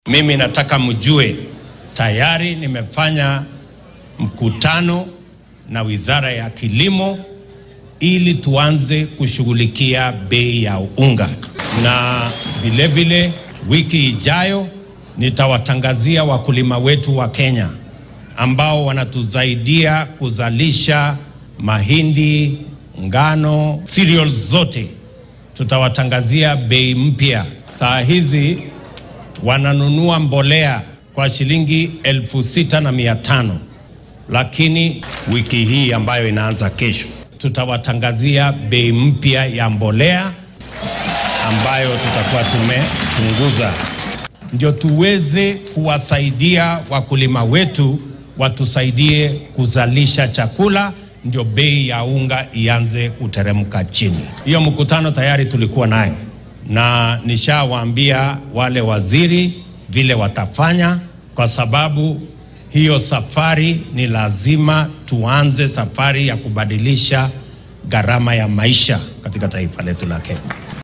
William Ruto ayaa arrimahan ka hadlay xilli uu shalay ku sugnaa magaalada Maua ee ismaamulka Meru.